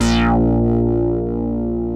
OSCAR 8 A#2.wav